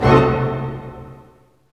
HIT ORCHD07R.wav